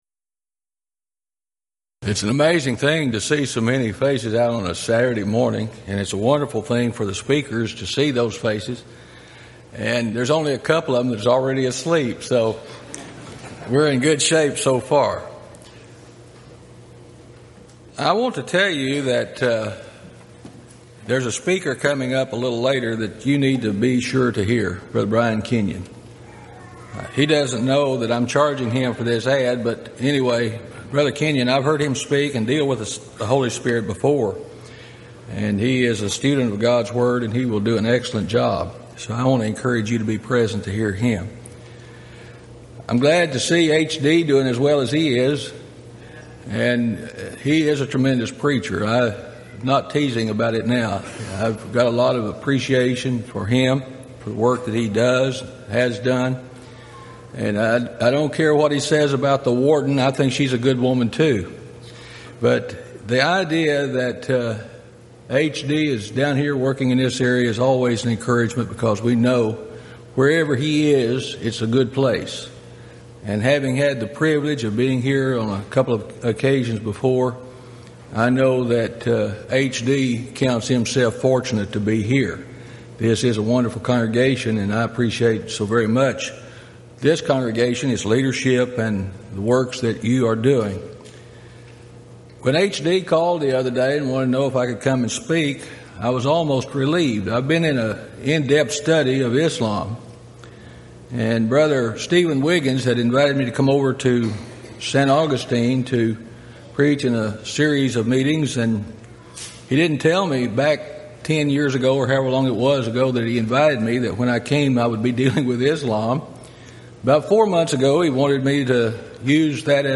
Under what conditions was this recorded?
Event: 23rd Annual Gulf Coast Lectures Theme/Title: The Holy Spirit